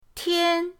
tian1.mp3